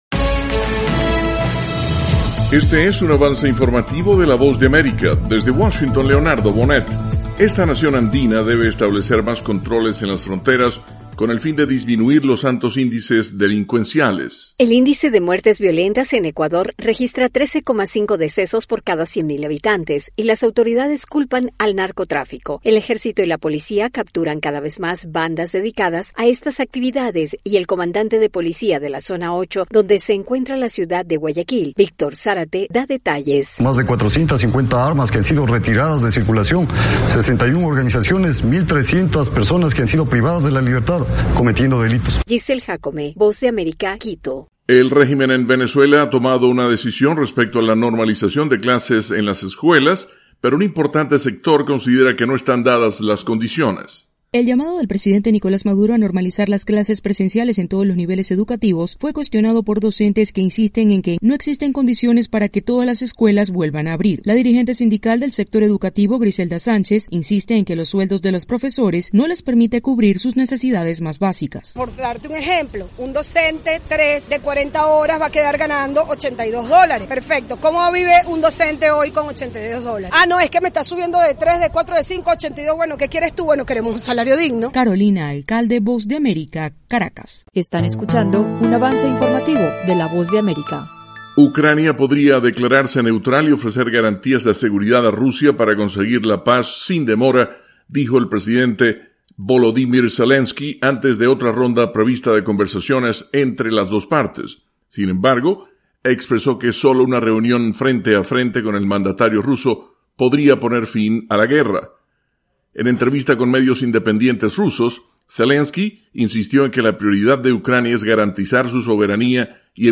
Última hora